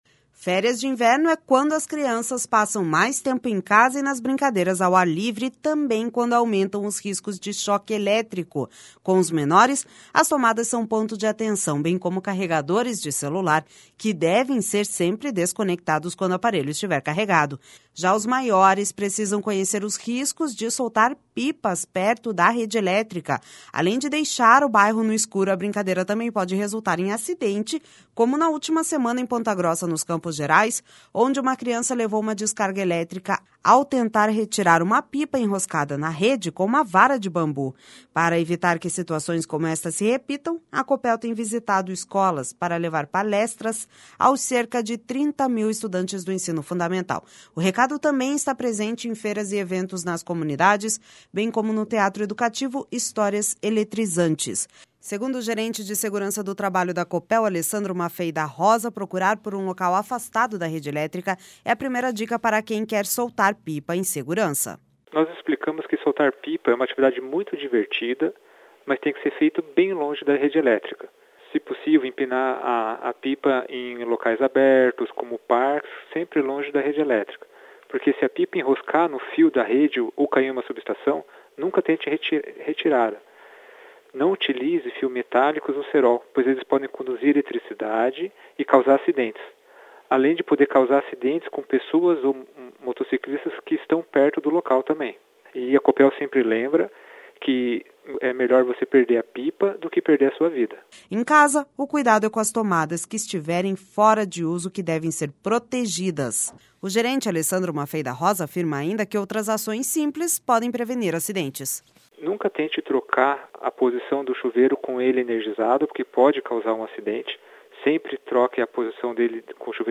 Férias de inverno é quando as crianças passam mais tempo em casa e nas brincadeiras ao ar livre, e também quando aumentam os riscos de choque elétrico.